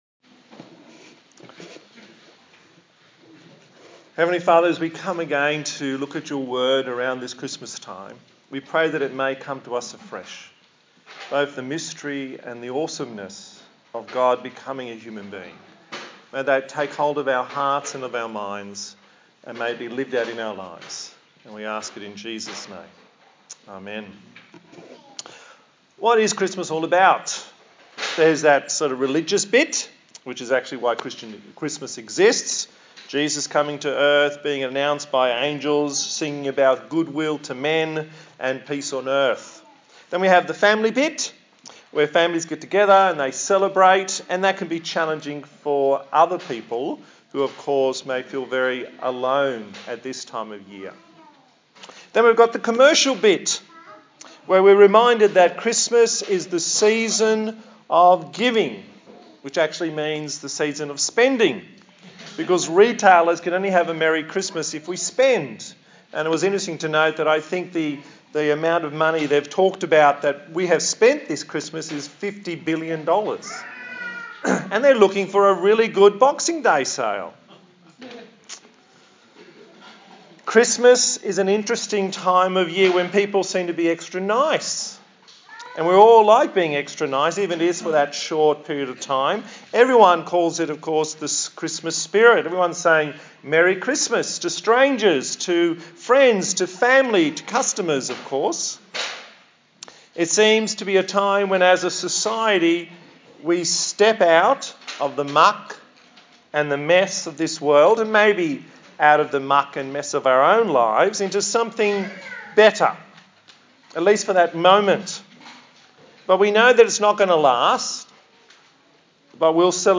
Christmas Passage: Matthew 1:18-25 Service Type: Sunday Morning